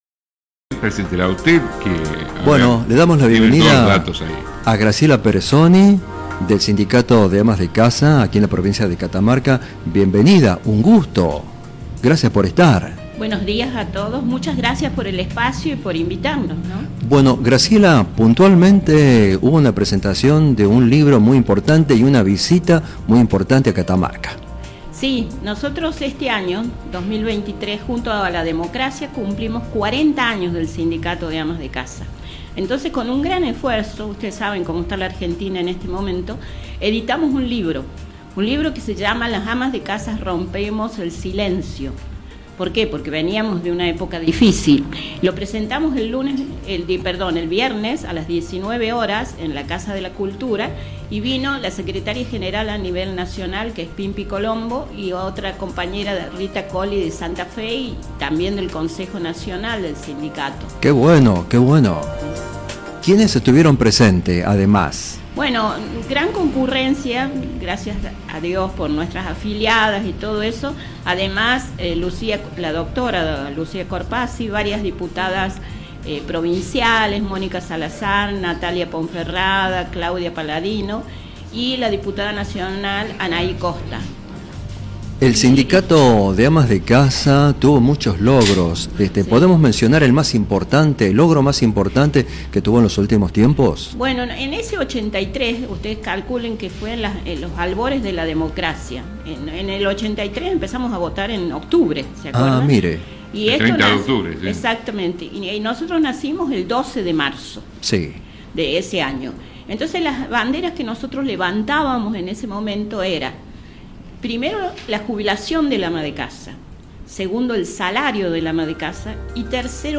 Entrevistas CityRadio CiTy Entrevistas